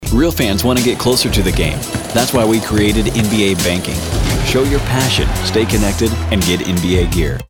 Male
English (North American)
Yng Adult (18-29), Adult (30-50)
Corporate
All our voice actors have professional broadcast quality recording studios.